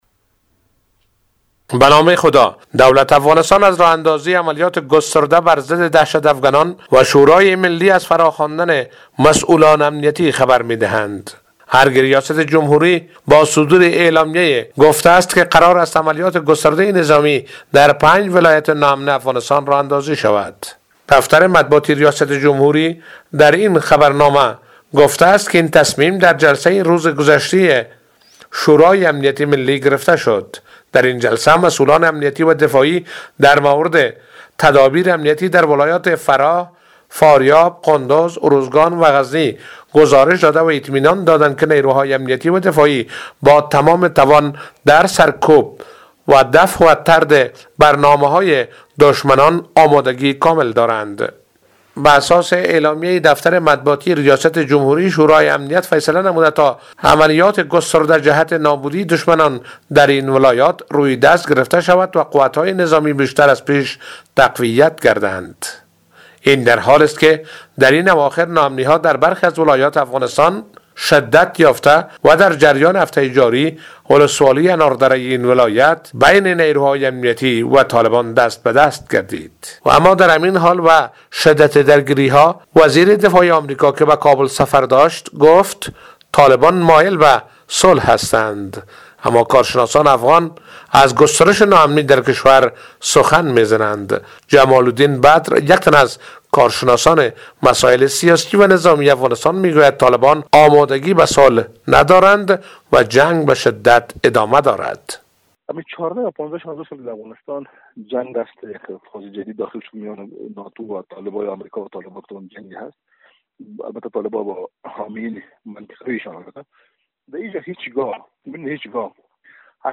گزارش : آغاز عملیان نیروهای دولتی افغانستان علیه طالبان در 5 ولایت